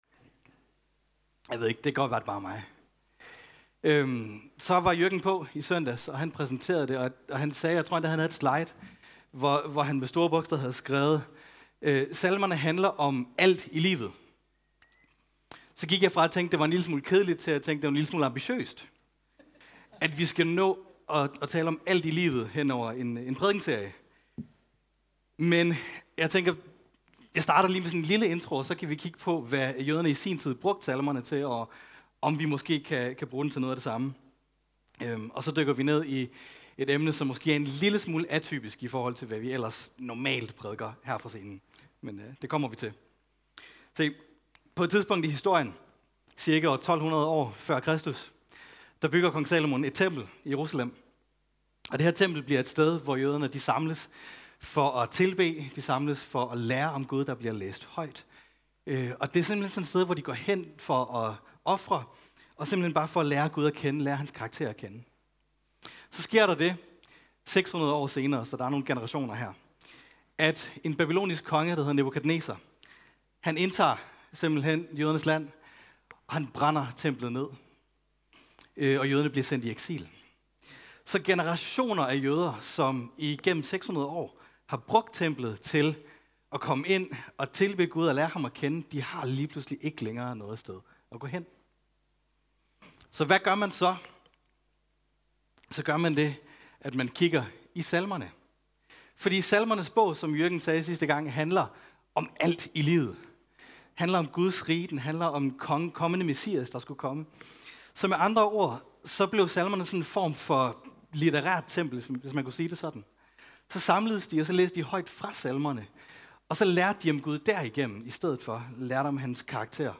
En prædiken fra tema "Salmerne."